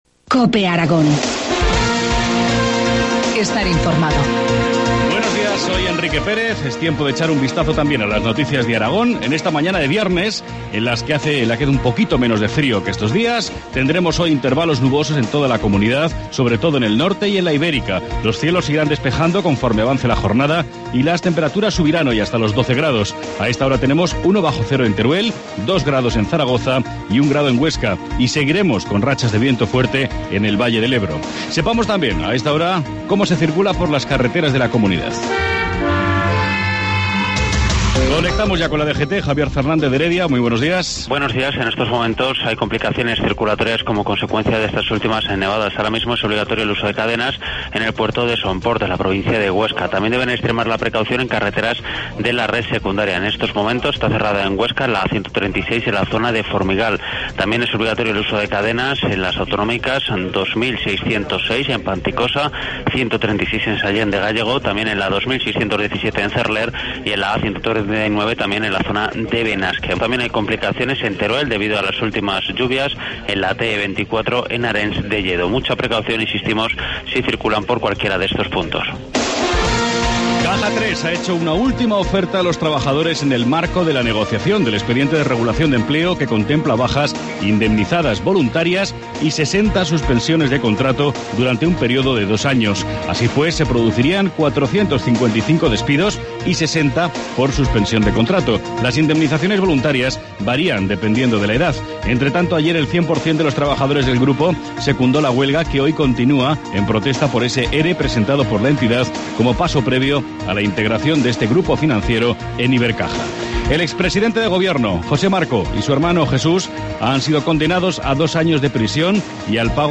Informativo matinal, viernes 15 de marzo, 7.25 horas